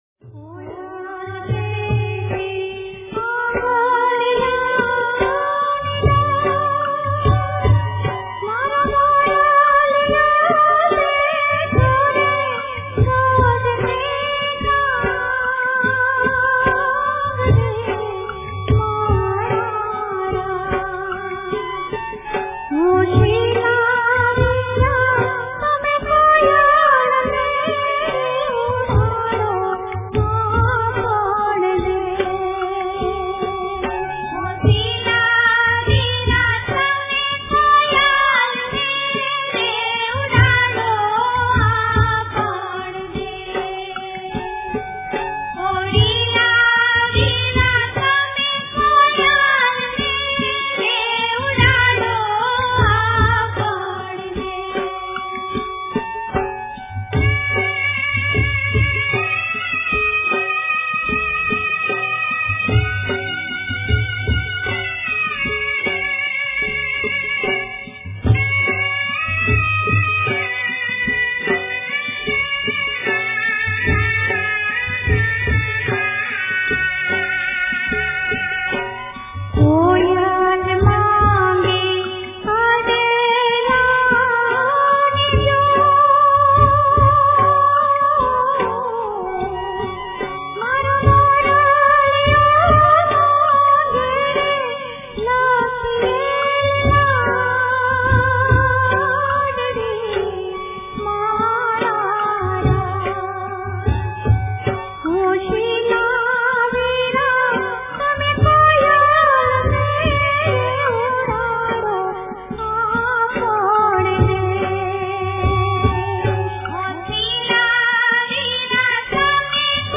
કોયલ બેઠી આંબલિયાની ડાળ (Koyal Bethi Ambaliyani Daad) - Lyrics - લગ્ન ગીત (Lagna Geet) - Gujarati World